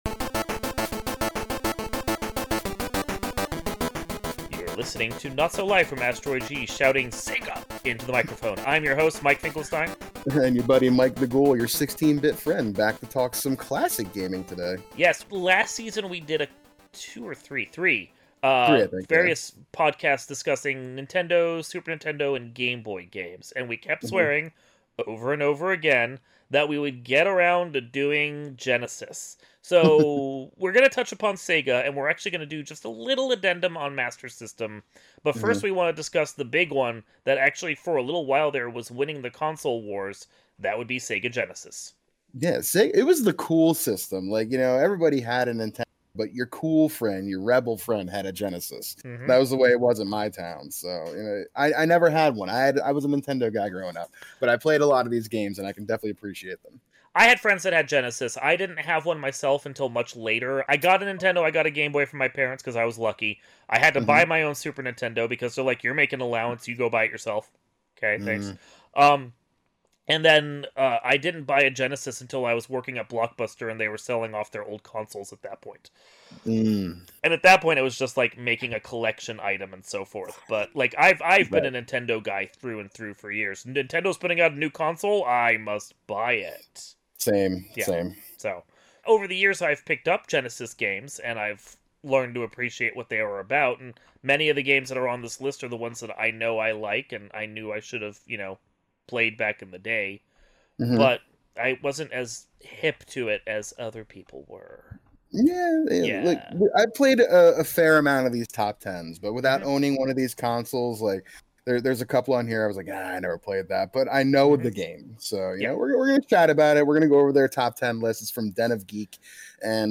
We're talking the Sega Genesis in this week's episode of (Not So) Live from Asteroid G. With a list of the best games in hand, two Nintendo fanboys do their best to gush about the best games of the 16-bit Sega era. Also, bonus content: Sega Master System.